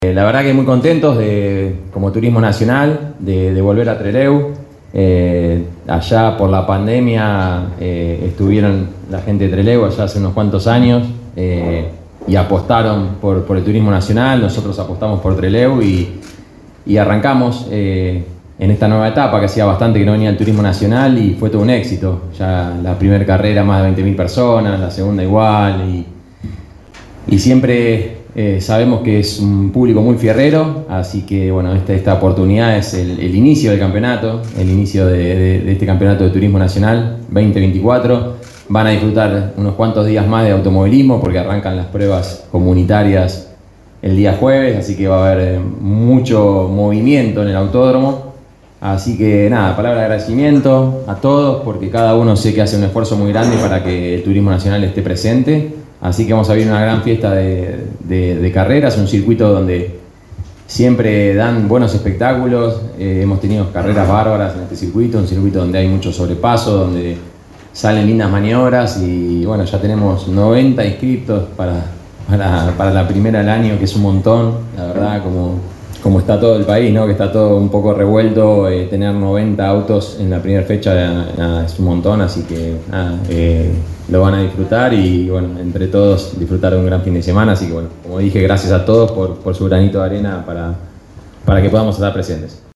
presentación de la competencia apertura del Turismo Nacional en Trelew